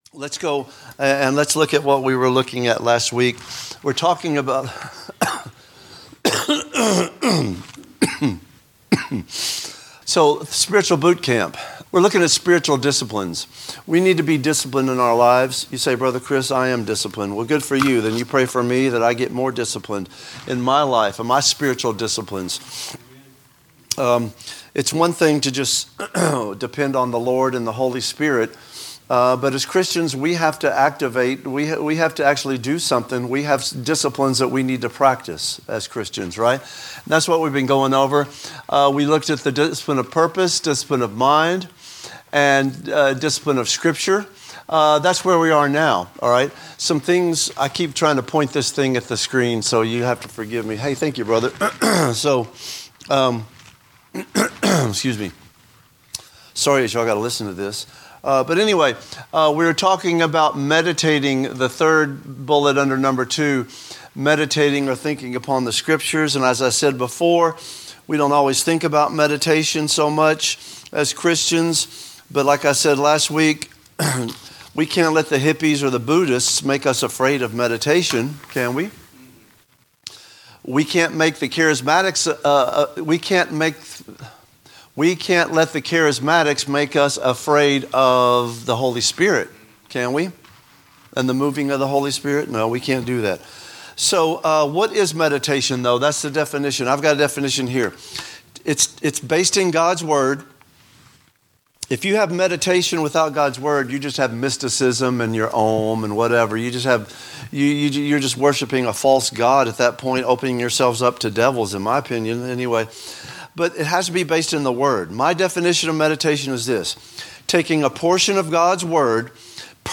A message from the series "Spiritual Disciplines."